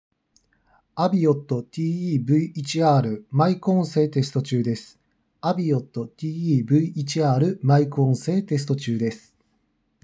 マイク性能は1万円以上だと普通に聞ける
自分自身のクリアな音声を相手に届けることができます。
✅「AVIOT TE-V1R」のマイク音声テスト